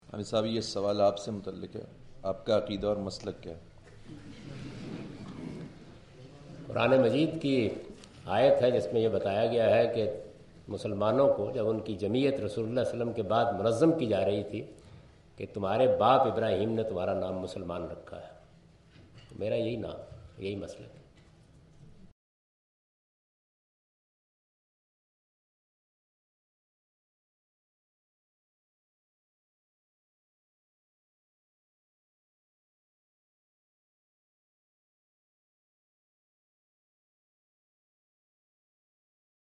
Javed Ahmad Ghamidi answer the question about "religious sect of Javed Ahmad Ghamidi" during his visit to Queen Mary University of London UK in March 13, 2016.
جاوید احمد صاحب غامدی اپنے دورہ برطانیہ 2016 کےدوران کوئین میری یونیورسٹی اف لندن میں "غامدی صاحب کس فرقے سے تعلق رکھتے ہیں؟" سے متعلق ایک سوال کا جواب دے رہے ہیں۔